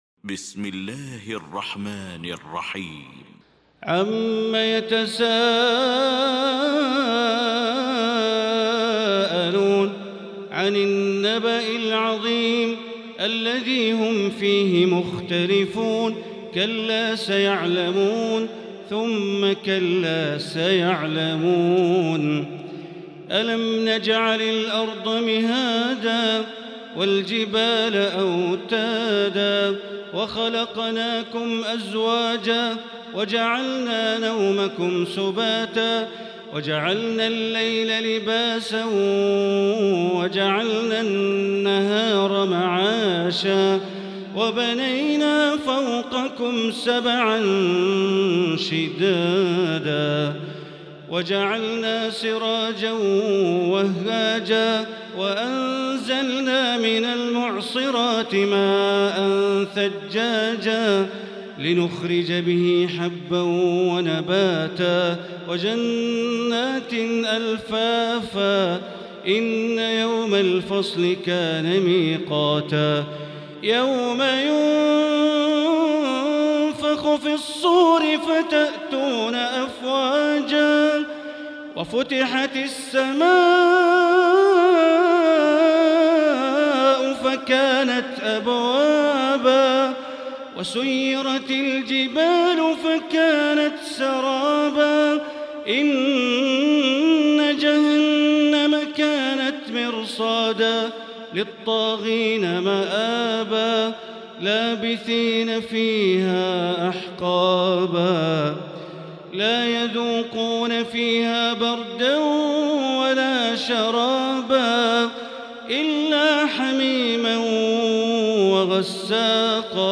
المكان: المسجد الحرام الشيخ: معالي الشيخ أ.د. بندر بليلة معالي الشيخ أ.د. بندر بليلة النبأ